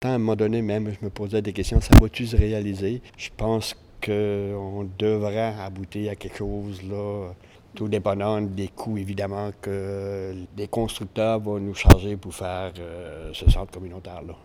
La Municipalité souhaite un début de chantier en 2026. Le conseiller municipal Bernard Richard est optimiste.